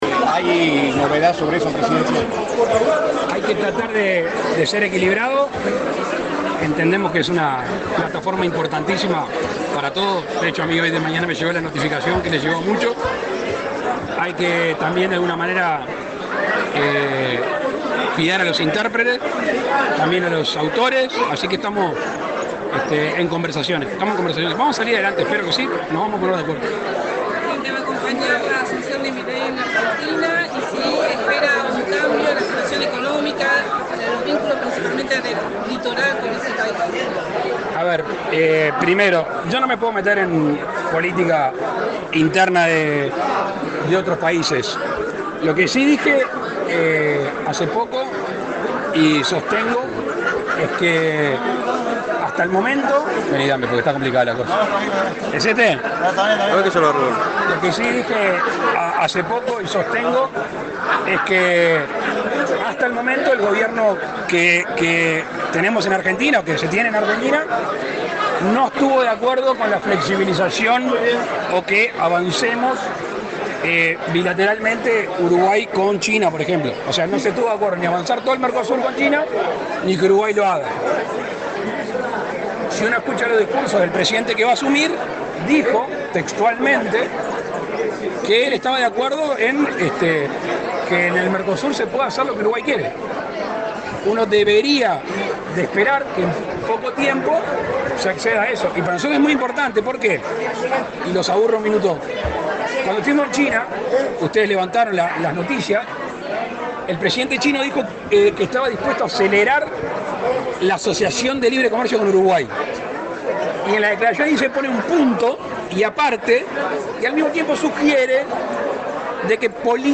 Declaraciones del presidente Lacalle Pou a la prensa
Declaraciones del presidente Lacalle Pou a la prensa 01/12/2023 Compartir Facebook X Copiar enlace WhatsApp LinkedIn El presidente Luis Lacalle Pou dialogó con la prensa, luego de encabezar el acto de inauguración de obras de electrificación rural en el paraje La Gloria, en el departamento de Cerro Largo.